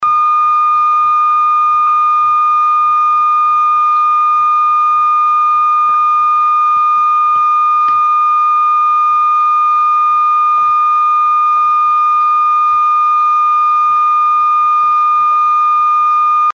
X-78 Power Transistor Oscillator